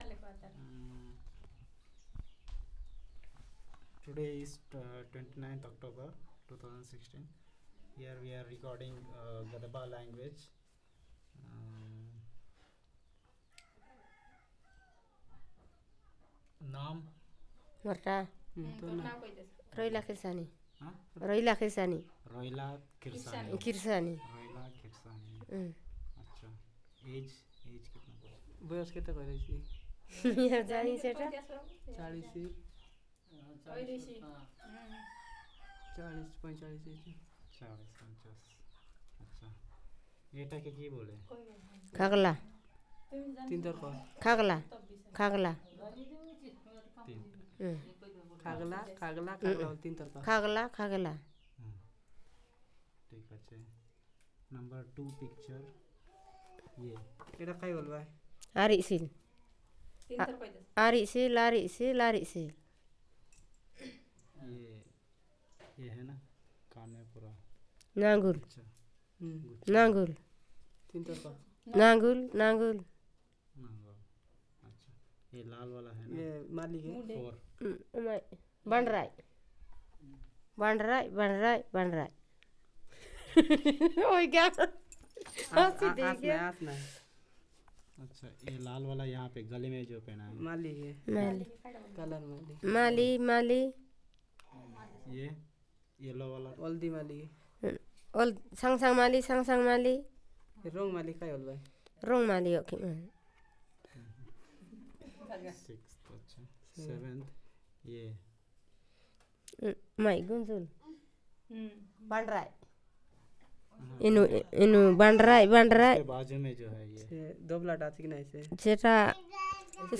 Elicitation of words about adornment and costumes